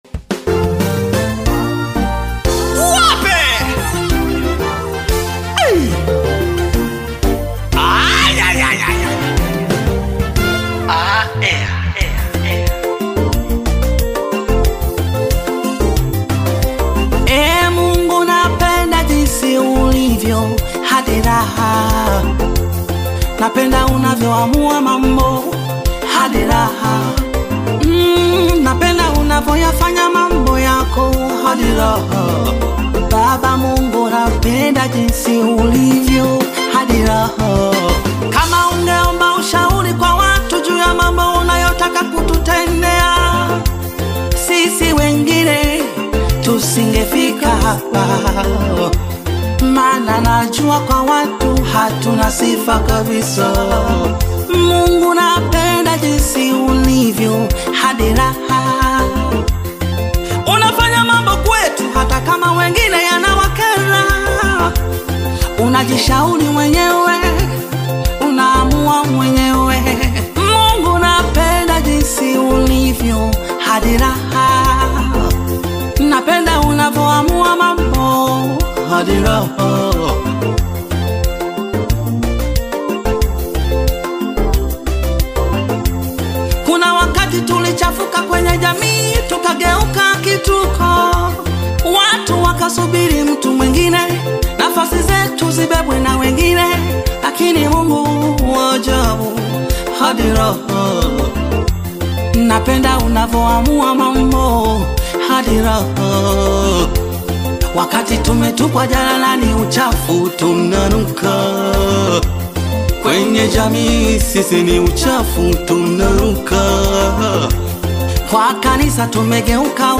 Tanzanian Gospel artist, singer and songwriter
Gospel song